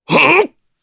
Oddy-Vox_Attack1_jp.wav